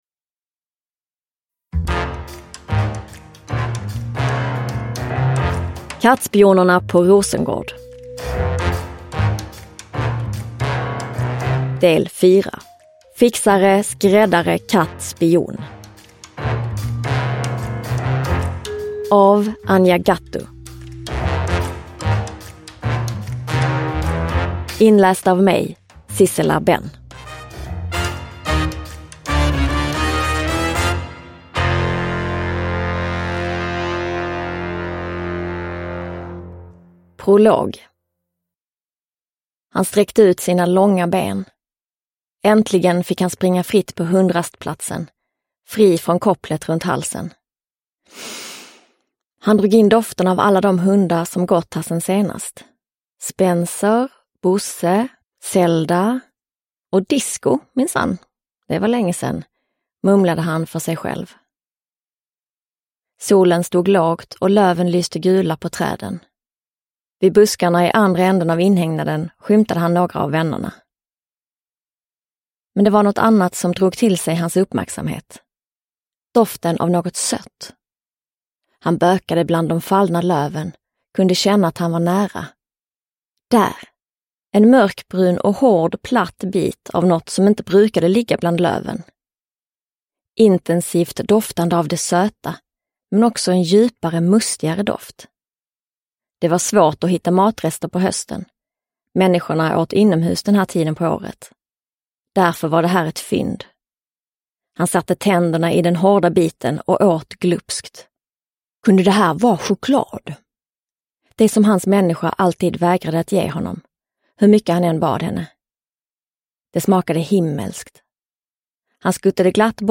Fixare, skräddare, katt, spion – Ljudbok – Laddas ner
Uppläsare: Sissela Benn